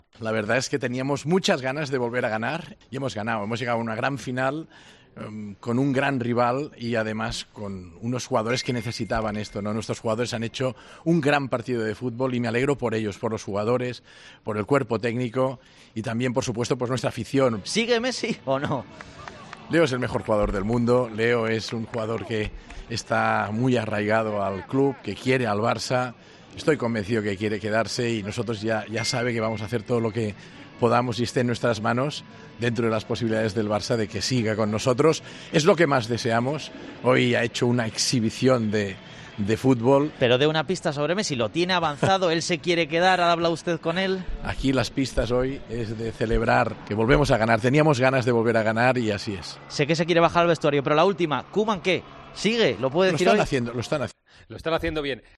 "Leo es el mejor jugador del mundo, está muy arraigado al club, quiere al Barça y estoy convencido de que quiere quedarse, y nosotros vamos a hacer todo lo que esté en nuestras manos para que siga", declaró durante la celebración del título.